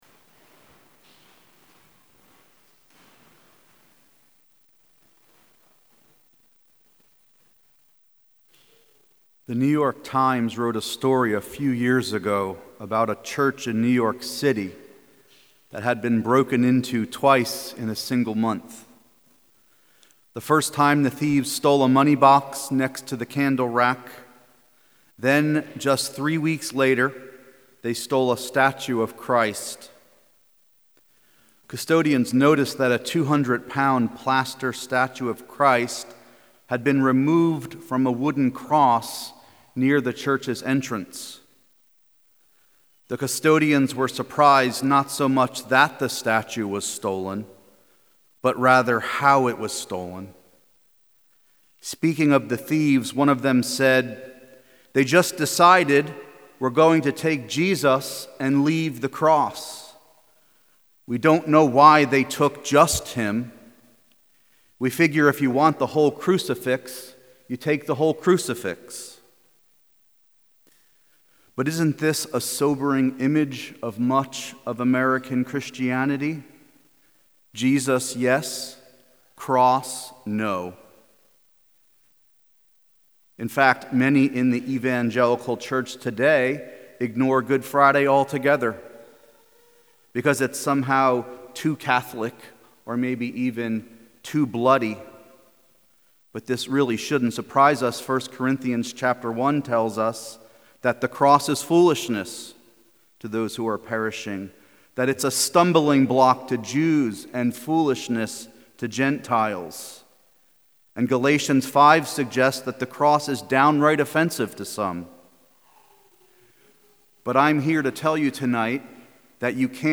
Sermons | Church of the Good Shepherd
A sermon